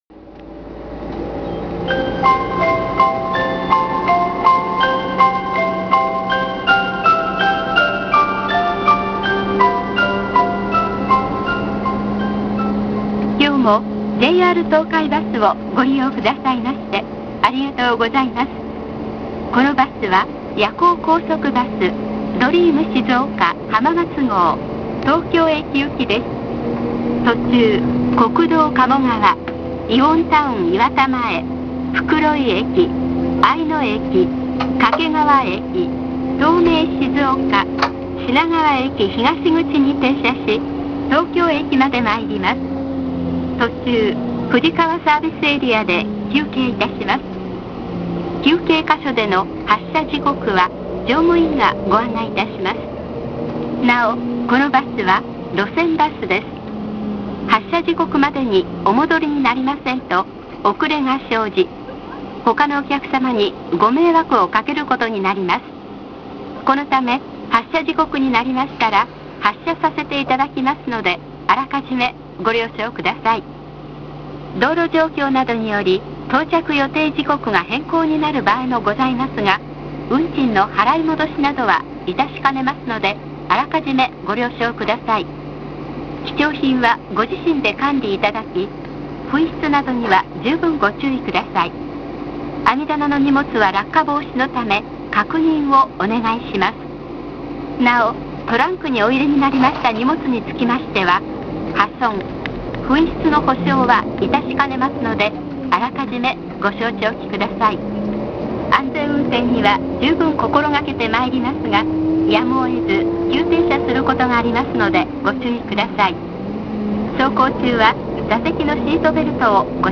ドリーム静岡・浜松号（東京行き）浜松駅出発後放送